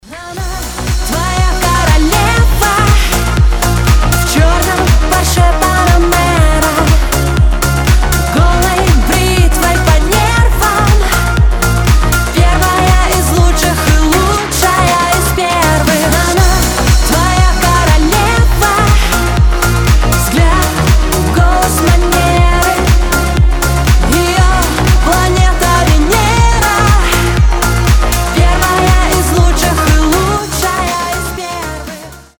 • Качество: 320, Stereo
зажигательные